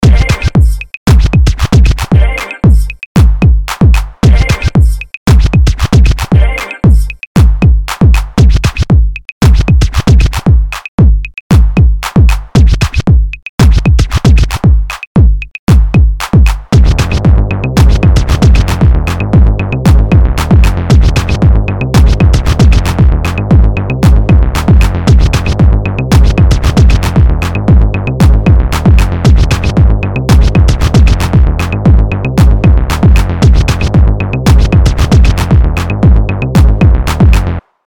inst hiphop
ベースのグルーブとピアノのシンプルさは結構イイ！